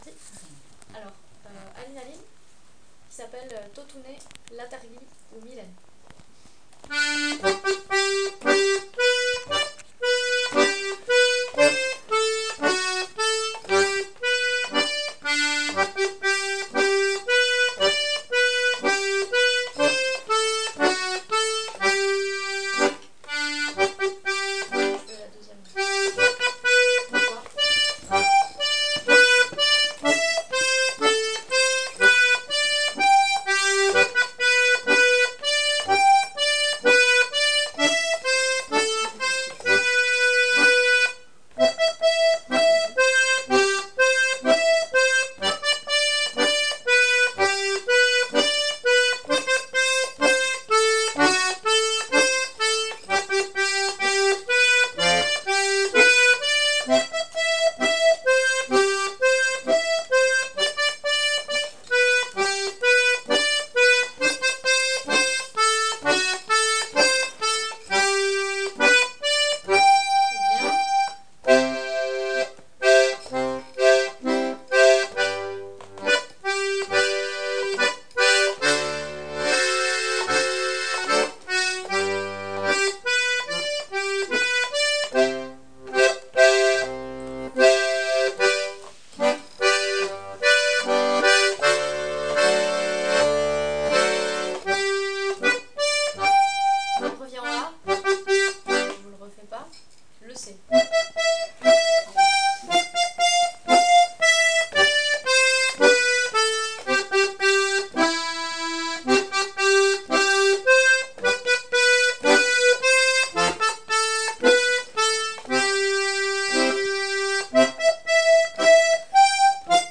l'atelier d'accordéon diatonique
ce morceau est basé sur des notes répétées en utilisant 2 doigts sur le même bouton.
Bien piquer les notes,